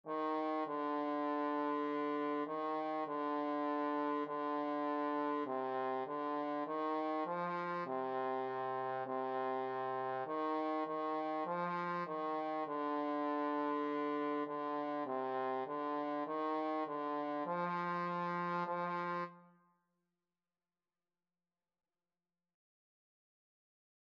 Time Signature: 4/4
Tempo Marking: =100 Score Key: Bb major (Sounding Pitch)
Range: Bb4-Eb5
Instrument: Trumpet
Style: Classical